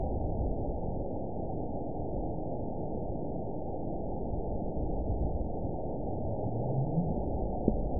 event 917884 date 04/19/23 time 23:00:54 GMT (2 years, 7 months ago) score 8.14 location TSS-AB04 detected by nrw target species NRW annotations +NRW Spectrogram: Frequency (kHz) vs. Time (s) audio not available .wav